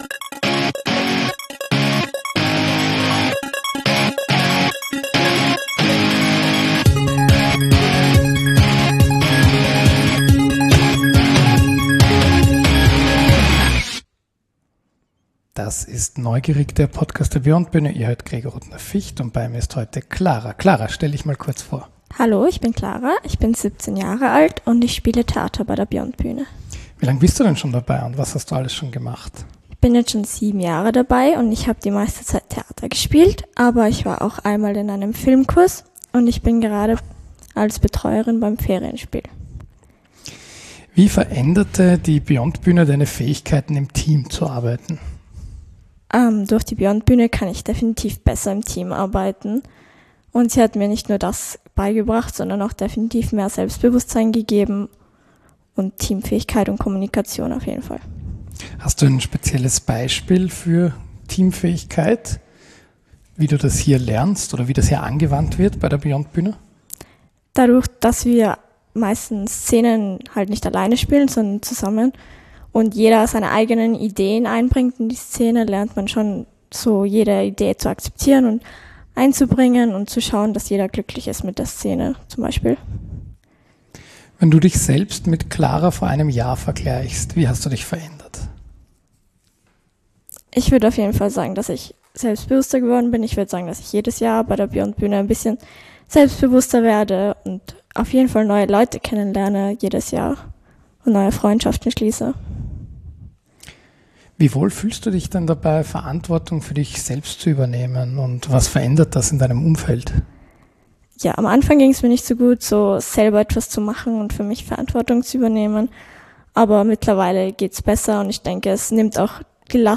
Interview mit unserem langjährigen Mitglied